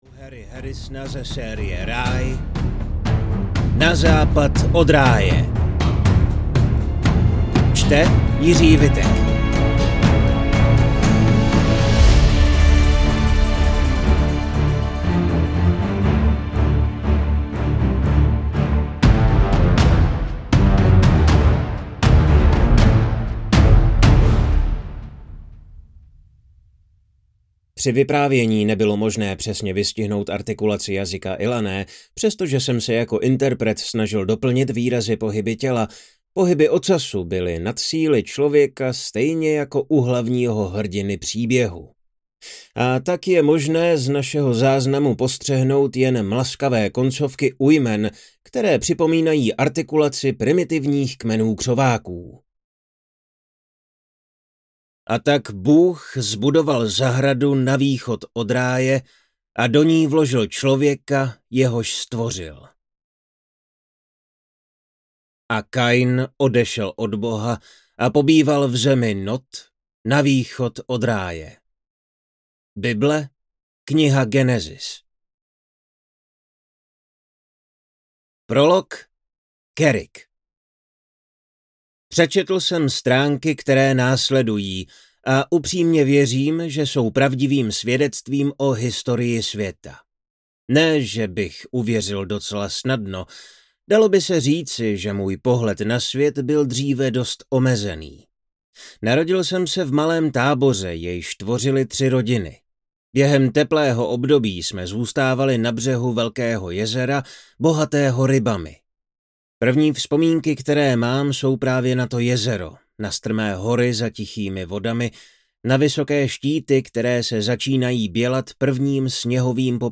Na západ od ráje audiokniha
Ukázka z knihy